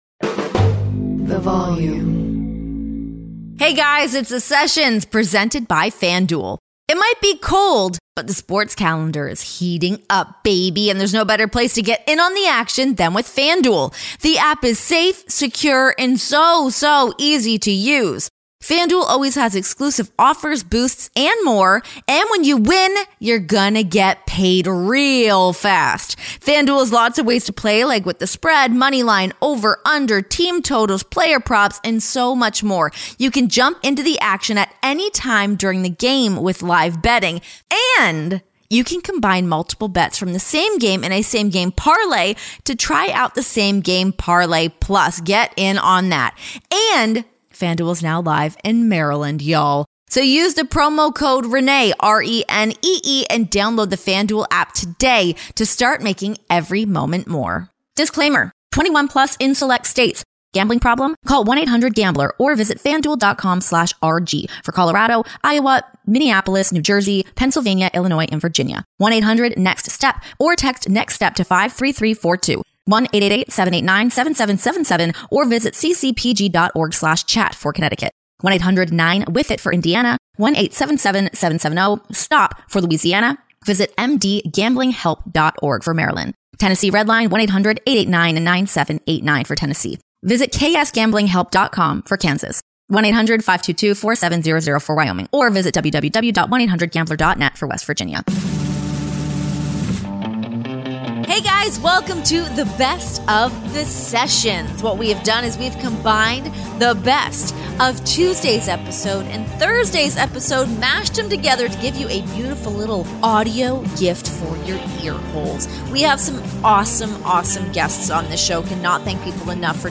Relive the best of the Sessions from 2022, featuring conversations with Jon Moxley and Bryan Danielson.